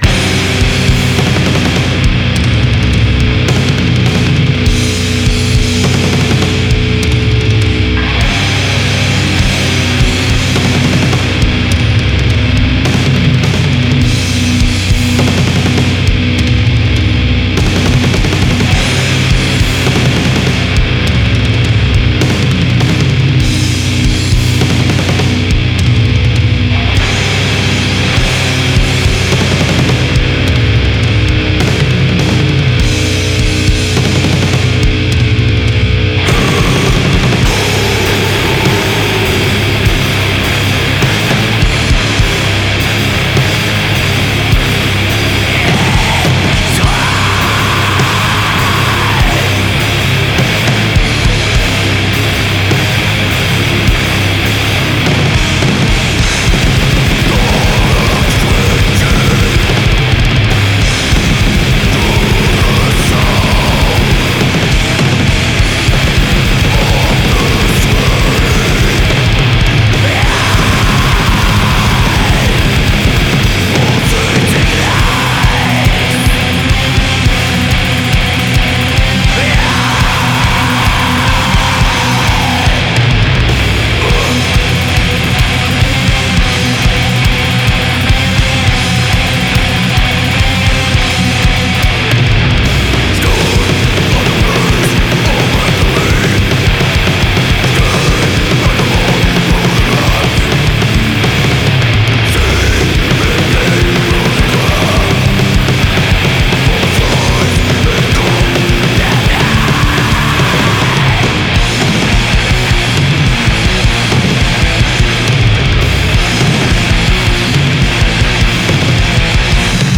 crushing death metal band